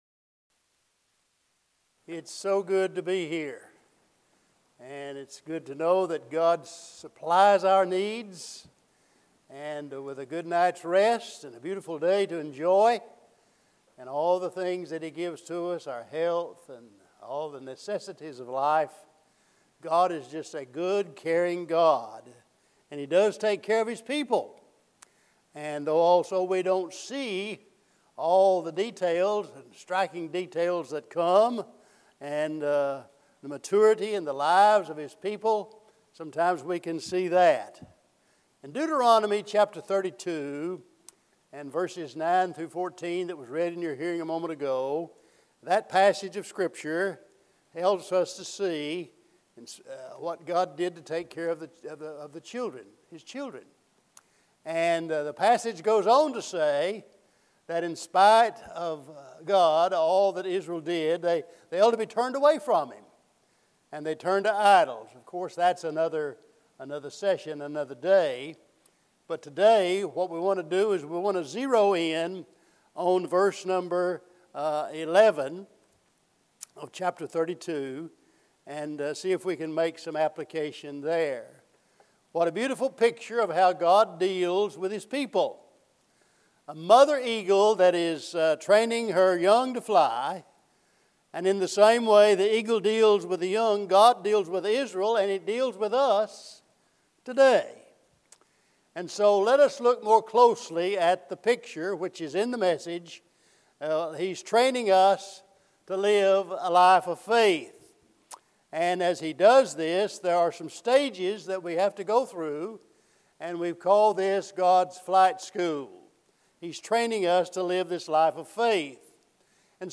Eastside Sermons Passage: Deuteronomy 32:11 Service Type: Sunday Morning « Walking Through the Bible